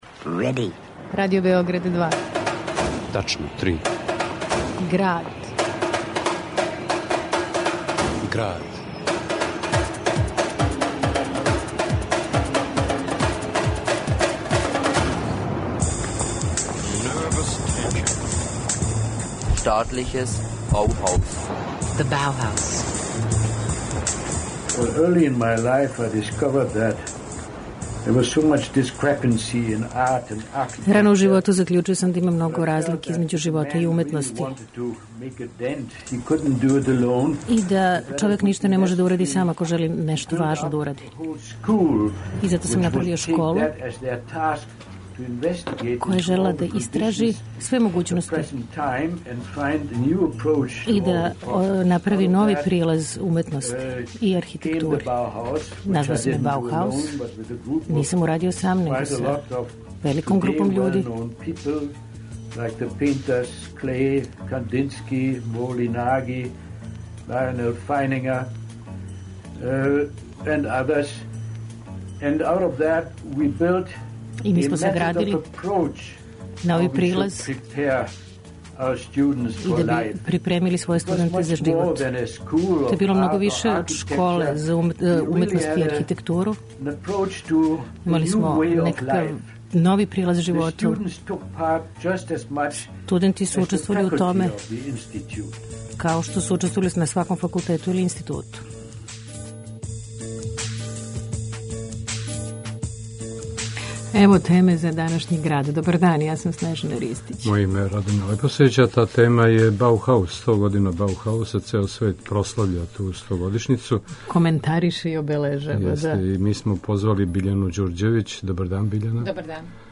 архитекта... а на архивским снимцима: Андреј Митровић - историчар, Валтер Гропиус и Мис ван дер Рое .